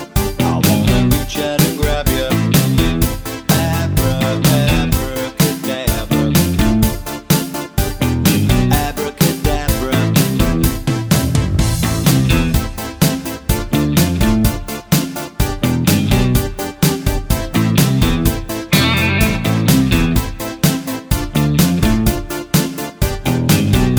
No High Harmony Soft Rock 3:45 Buy £1.50